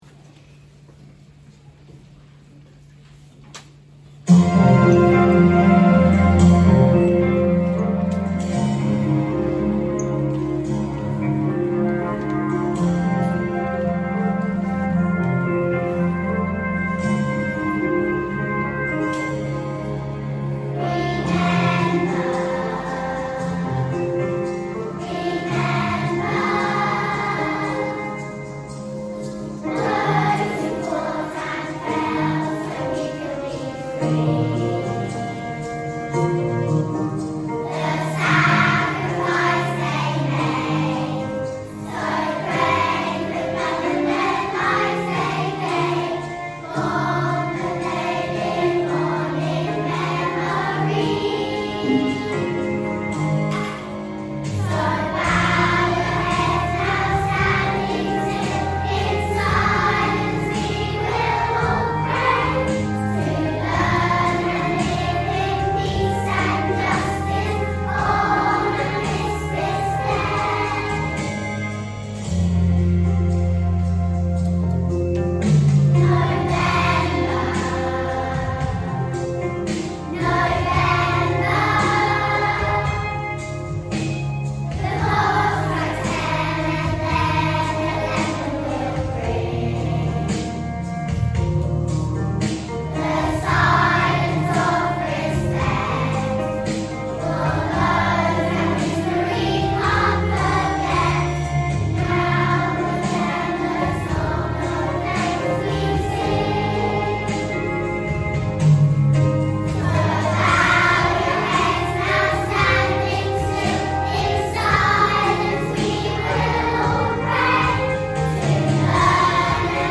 Remember | Y2/3 Choir